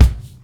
64FUNNY-BD-R.wav